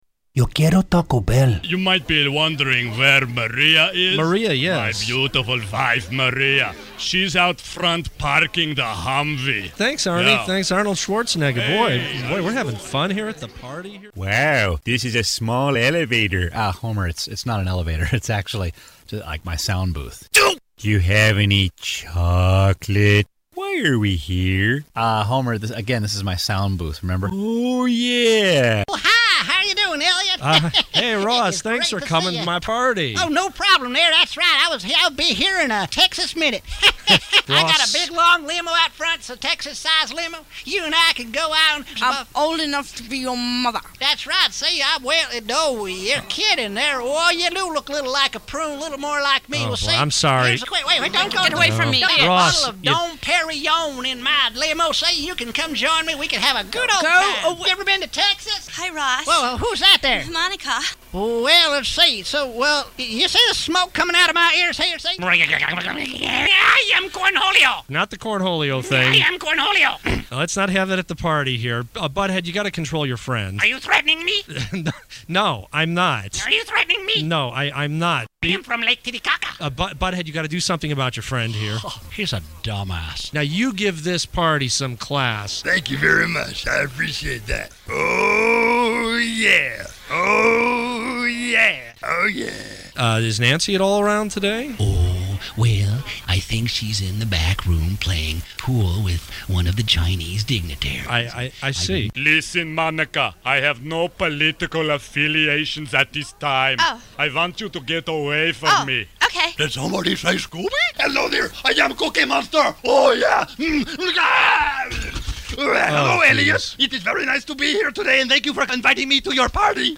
Impressions
Voice Over